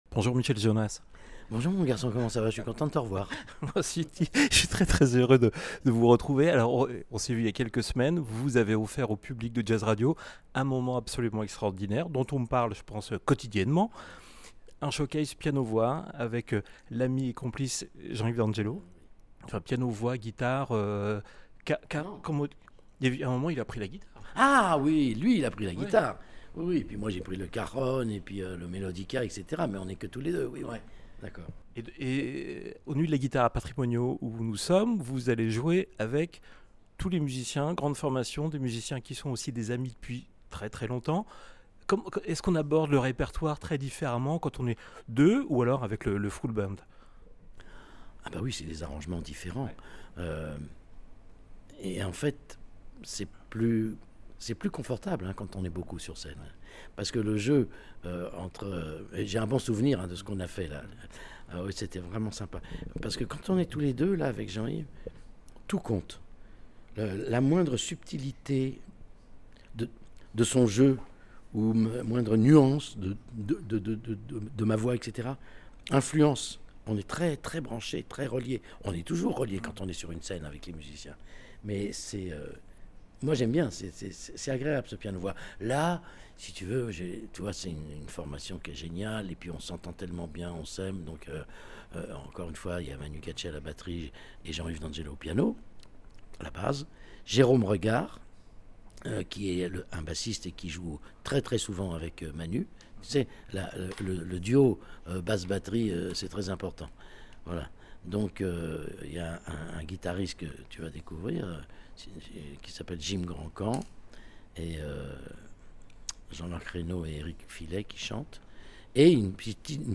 Écoutez l'interview de Michel Jonasz à l'occasion du festival des Nuits de la Guitare de Patrimonio pour cette saison 2025 !